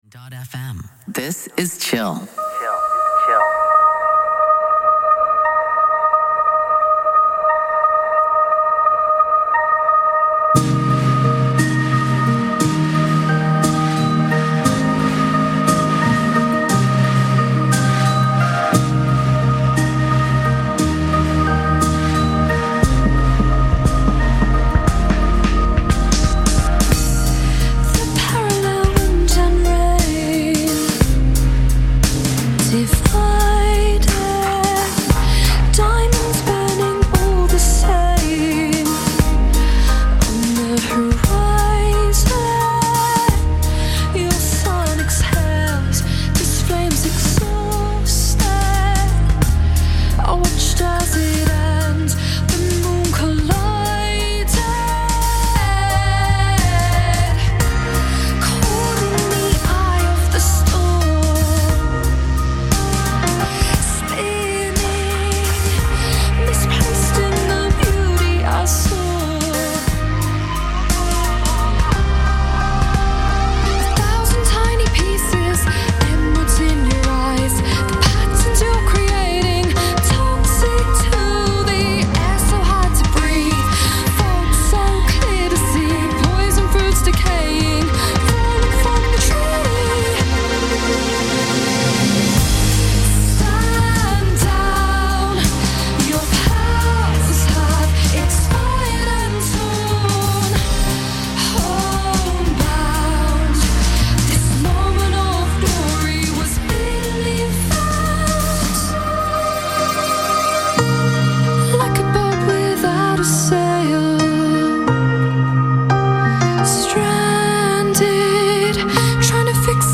Жанр: Electronica-Chillout